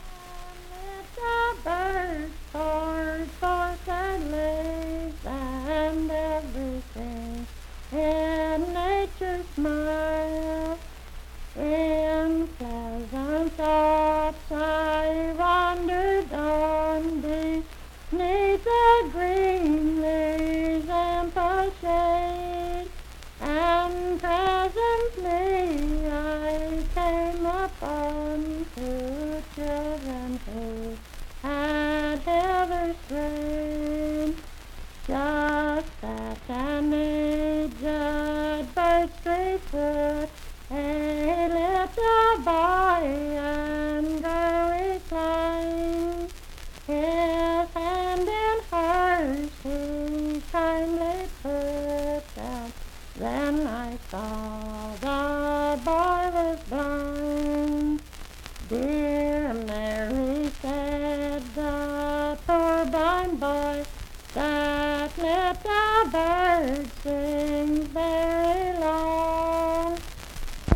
Unaccompanied vocal music
in Uffington, W.V..
Voice (sung)
Monongalia County (W. Va.)